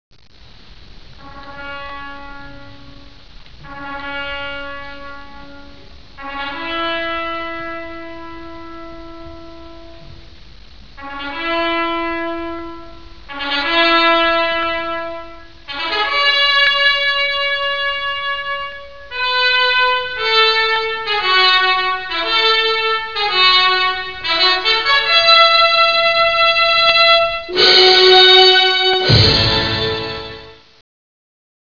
Solo di tromba